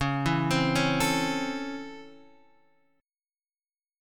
DbmM7#5 chord